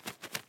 assets / minecraft / sounds / mob / parrot / fly8.ogg
fly8.ogg